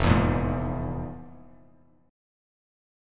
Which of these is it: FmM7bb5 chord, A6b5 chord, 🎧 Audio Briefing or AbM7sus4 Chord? FmM7bb5 chord